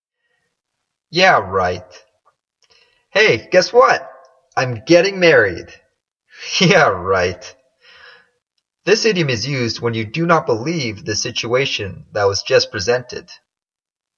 英語ネイティブによる発音は下記をクリックしてください。